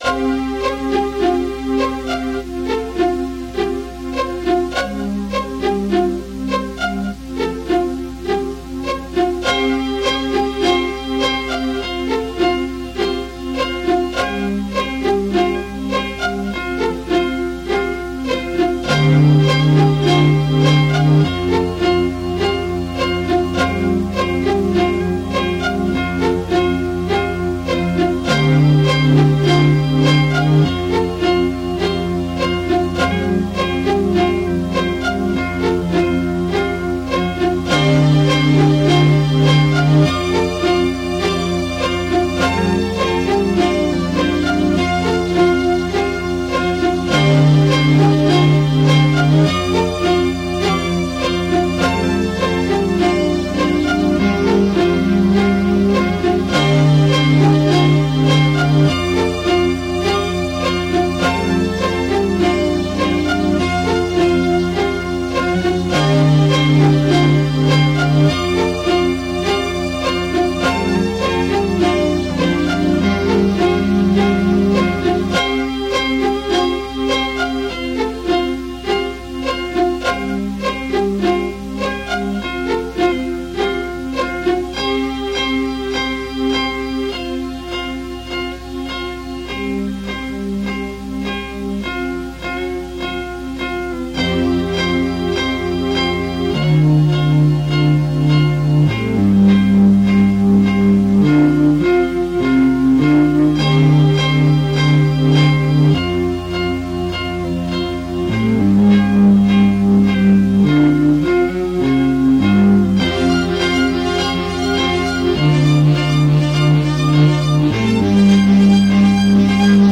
vaporwave, chiptune, videogame music, vgm, midiwave,